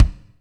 KIK M R K04L.wav